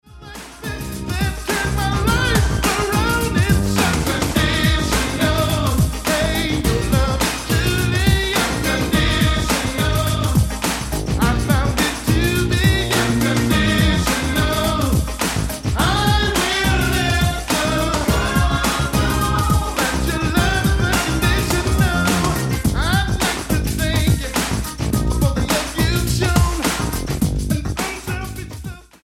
STYLE: Gospel
Contemporary gospel at its finest.